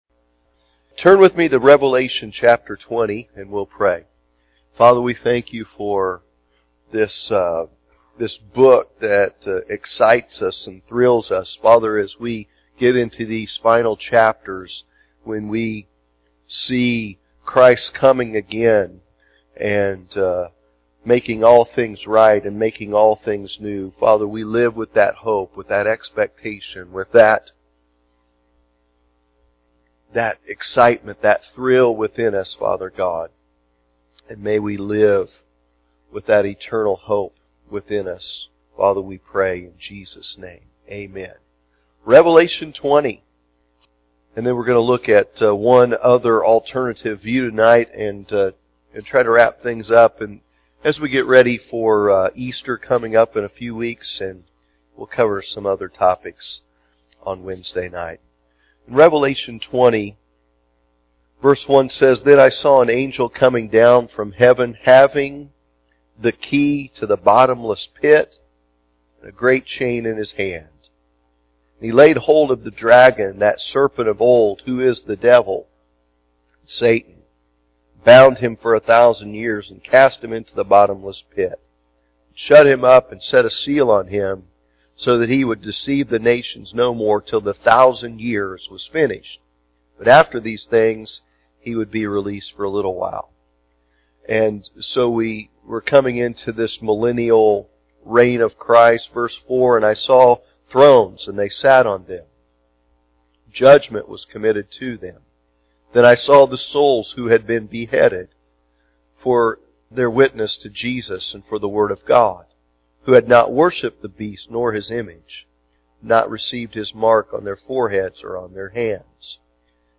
The Four Views of Revelation Wednesday Evening Service